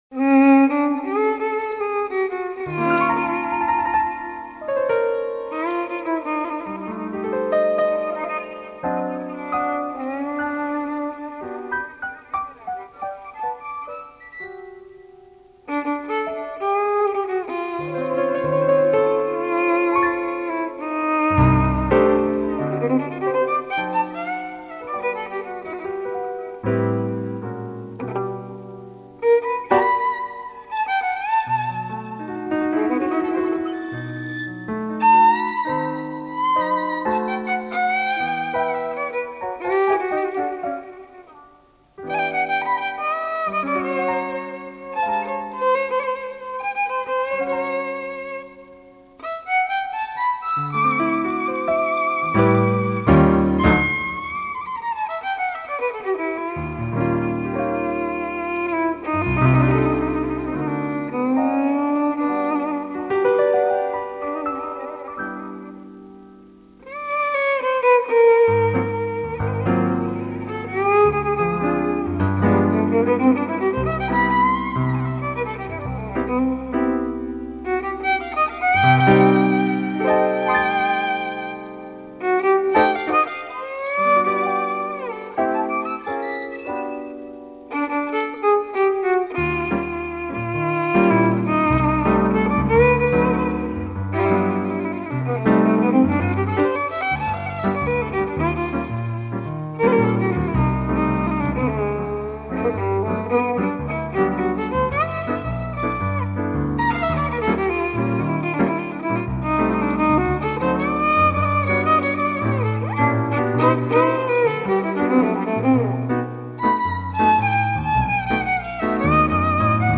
Duo  Piano / Violon
Un jazz chaleureux, porté par deux excellents musiciens.
Piano
Violon